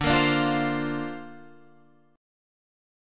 Dm7#5 chord